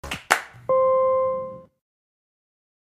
大空と空港の壮大さを感じさせる。